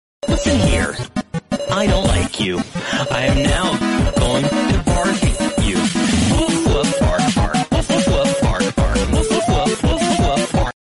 Mangopull Barking Sound Effects Free Download
mangopull barking sound effects free download